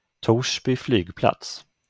Аэропо́рт Торсби (швед. Torsby Flygplats, произношение: [ˈtuːʂbʏ ˈflyːɡplats]